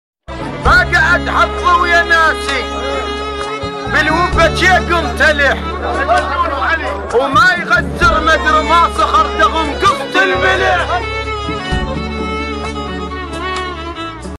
الشاعر والمهوال